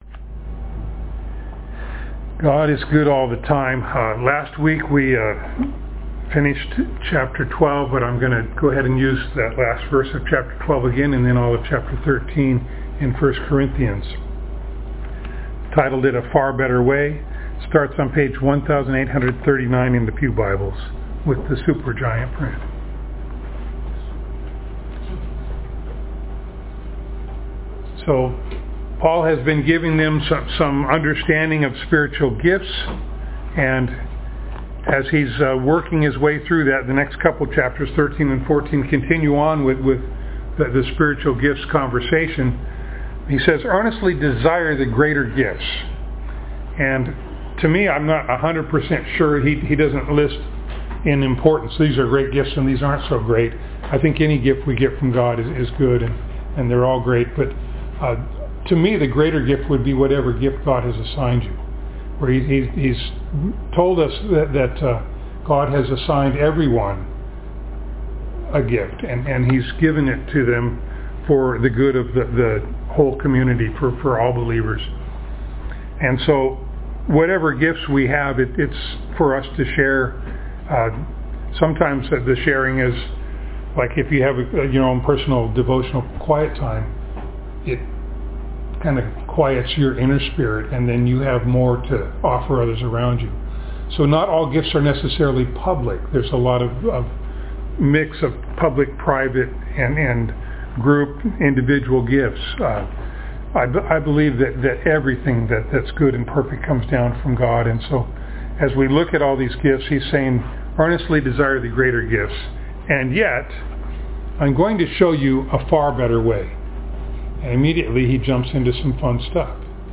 1 Corinthians 12:31-13:13 Service Type: Sunday Morning Download Files Notes « Spiritual Gifts